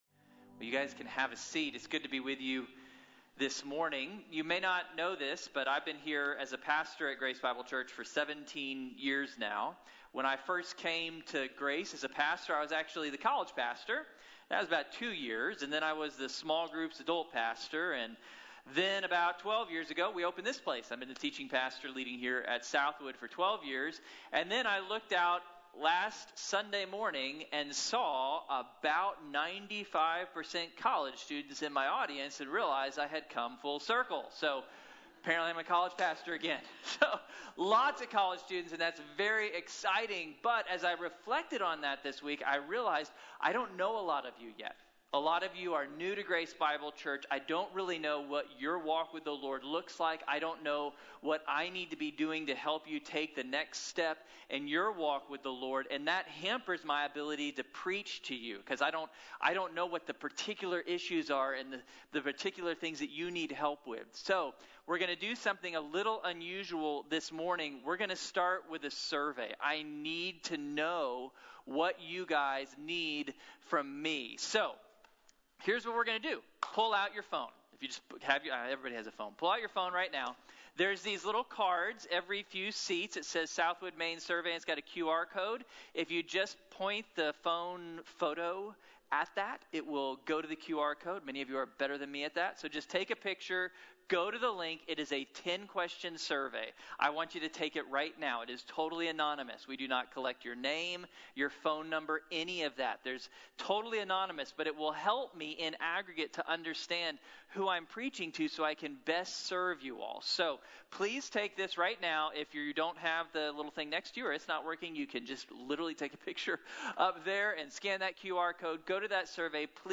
Every Knee | Sermon | Grace Bible Church